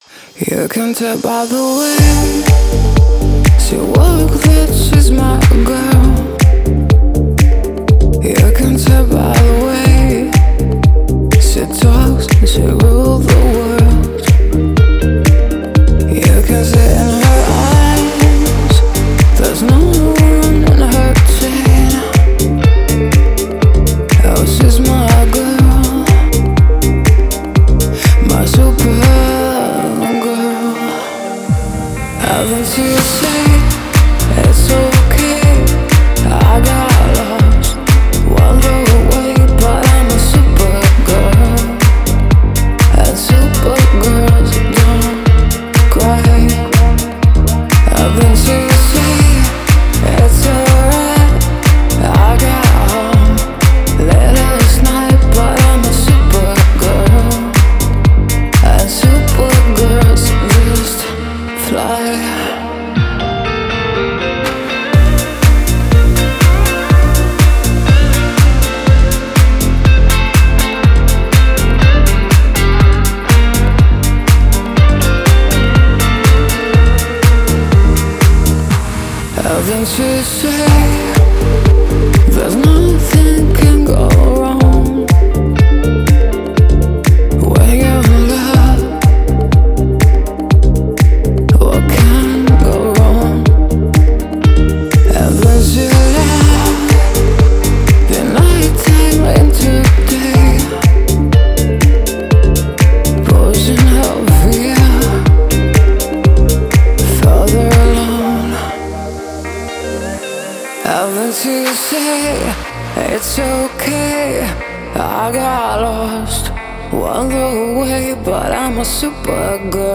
ремейки
каверы